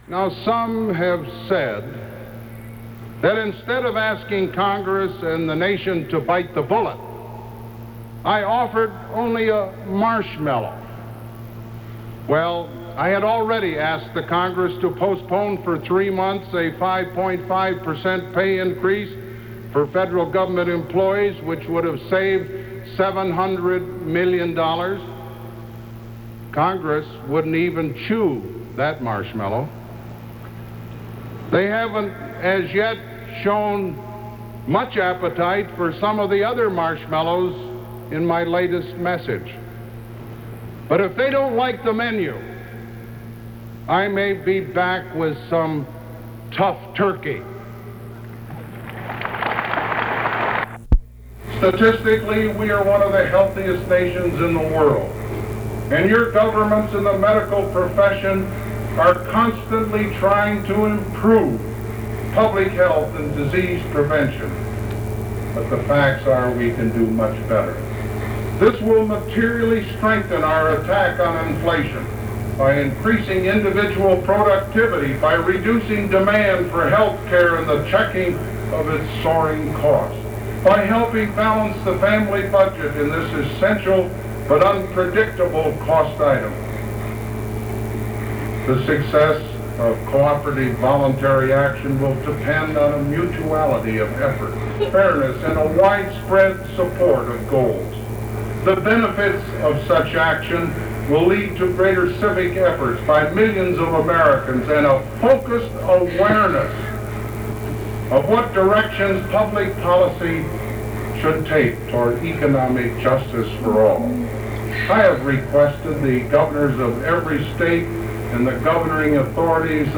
Gerald Ford delivers an economic message to a 4-H convention in Kansas City, Missouri